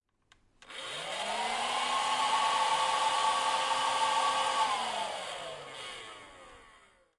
工作室 " 真空清洗机
描述：吸尘器